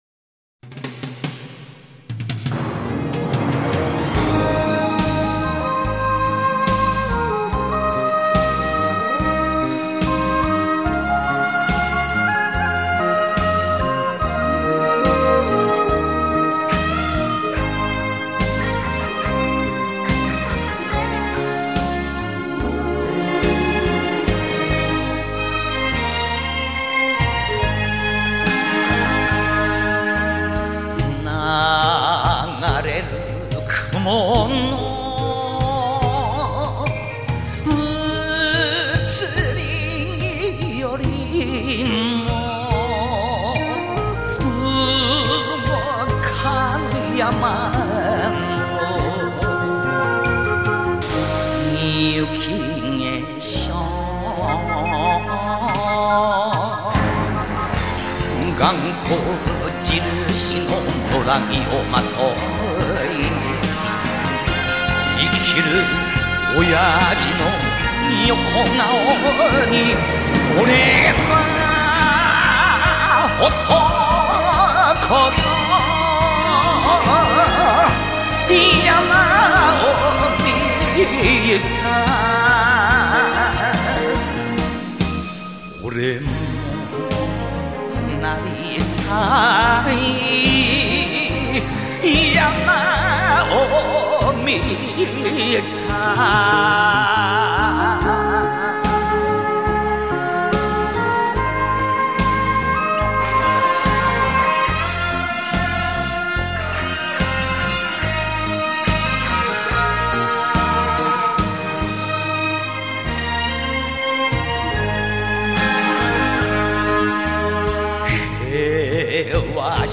他将传统民间歌谣和现代流行曲的唱法熔为一炉，形成了自己独特的演唱技法。